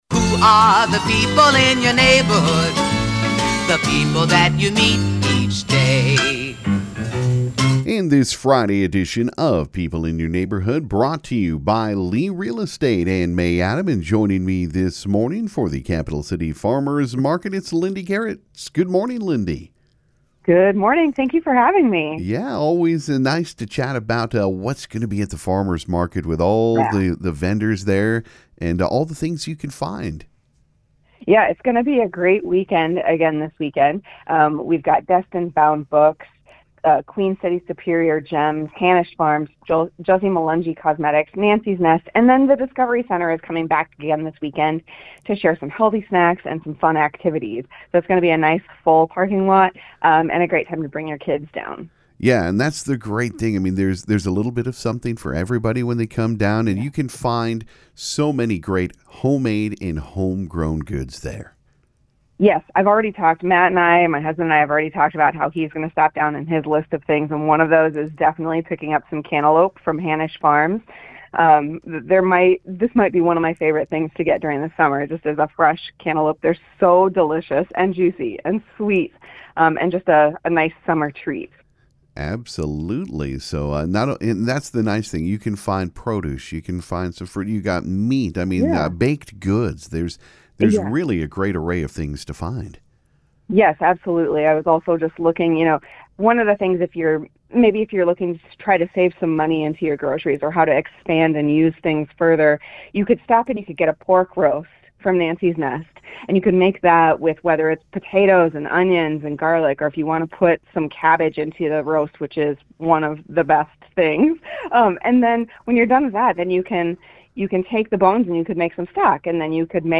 Capital City Farmer’s Market chat